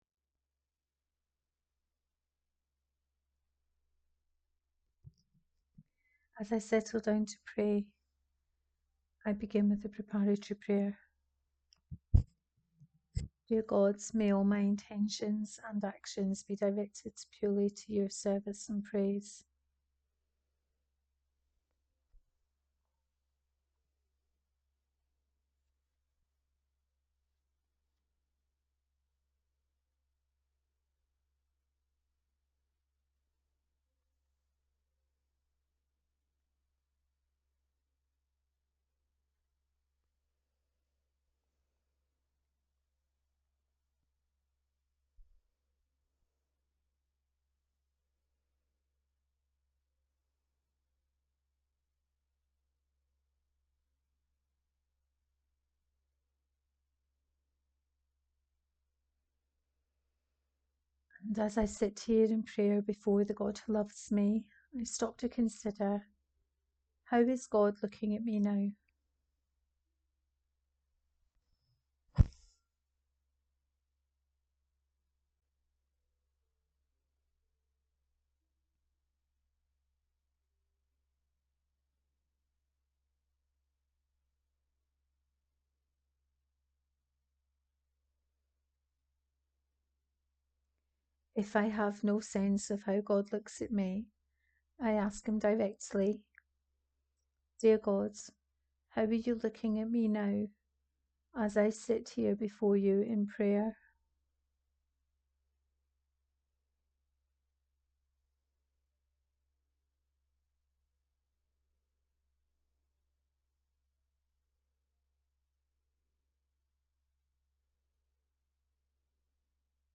40 Day Journey with Julian of Norwich: Day 3 Guided prayer.
The guided prayer is also available as a podcast on the channel: Sunflower Seed Spirituality